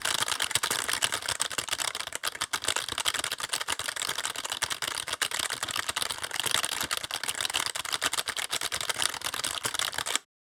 Keyboard_1_fast_tapping_01
Keyboard_1_fast_1.wav